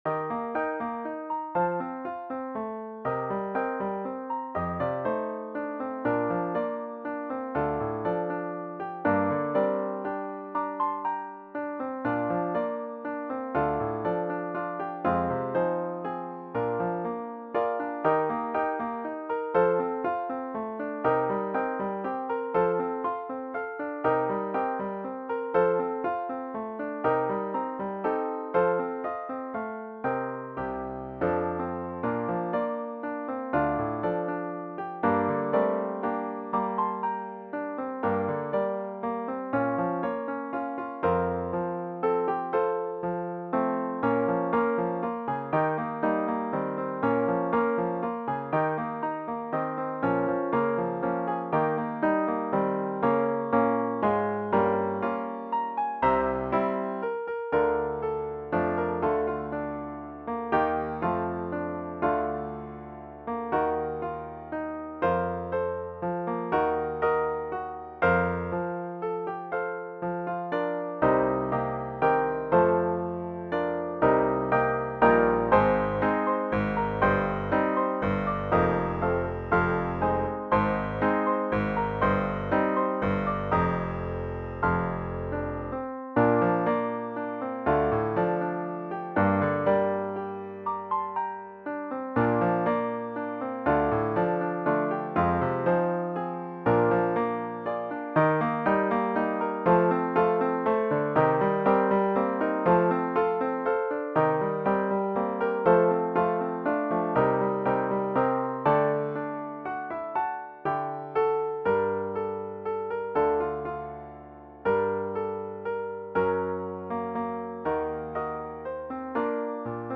- Bardzo łatwa piosenka tylko z głosem